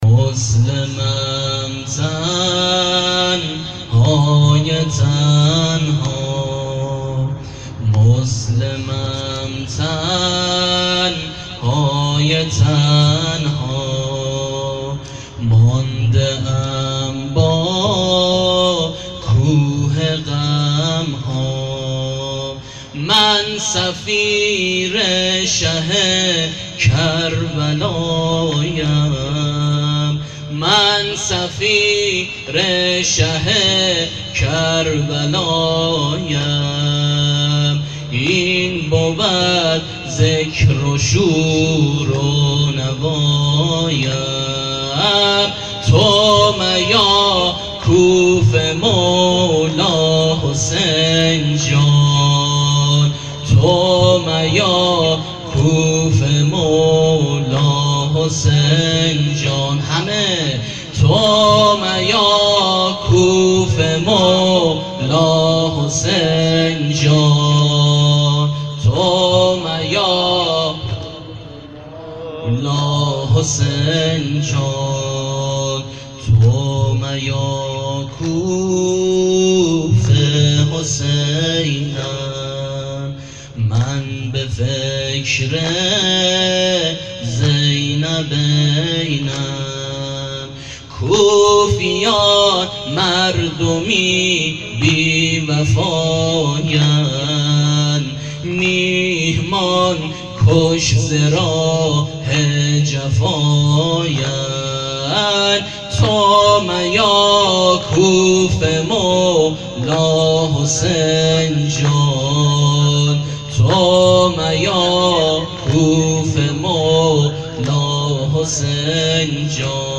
نوحه حضرت مسلم ابن عقیل(ع)محرم1399